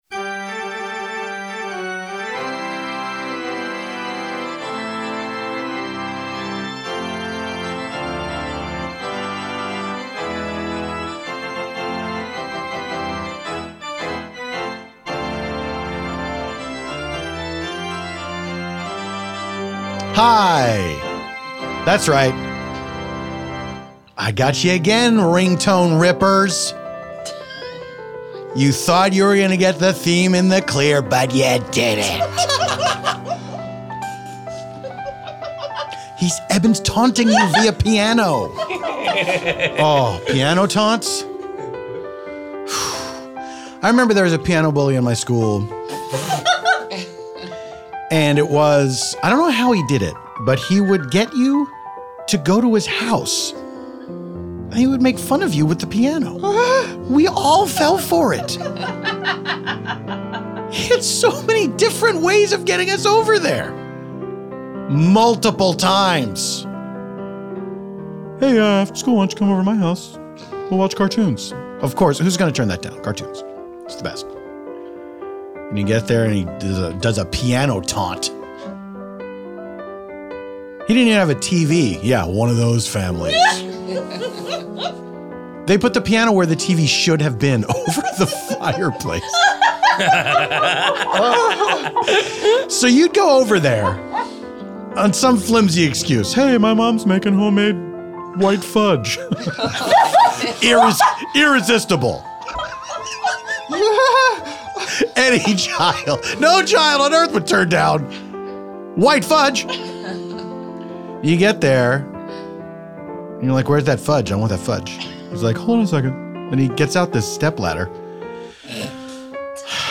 This time out, Paul's special guest is fantastic performer/drag superstar Trixie Mattel! Trixie chats about the last time he was in a stream, nature related likes/dislikes growing up in the " country country," and the effects of cryotherapy.